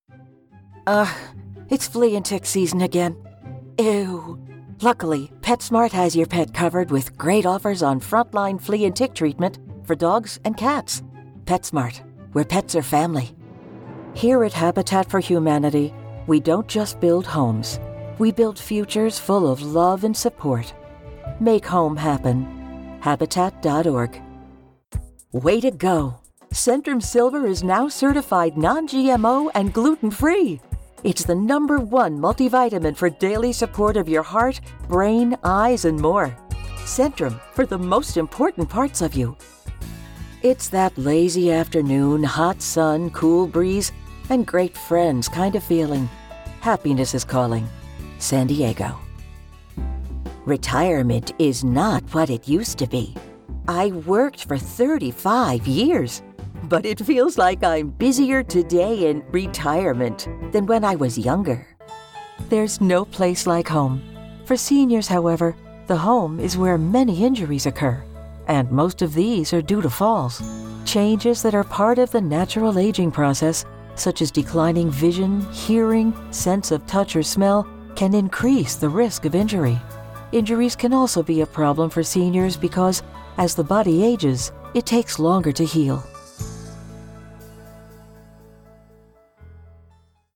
Commercial demo
English - USA and Canada
Great Voice Certified Home Studio